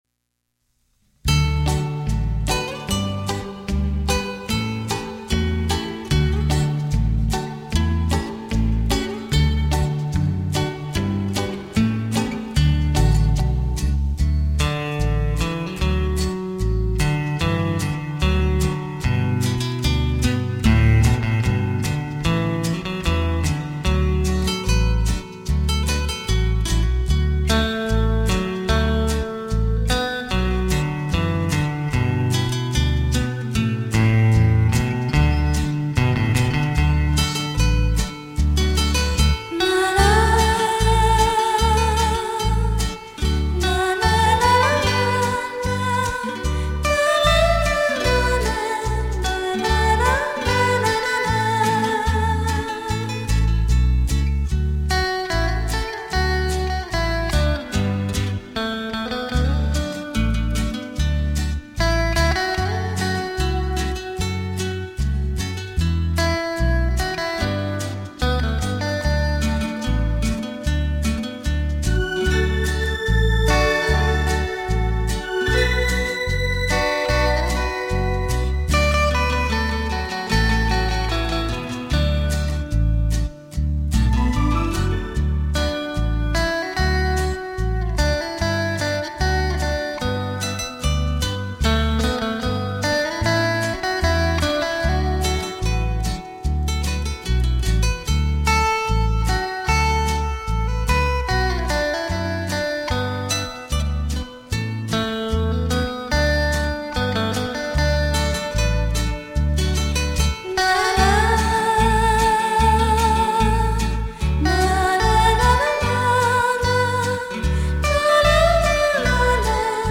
畅销东洋演歌名曲演奏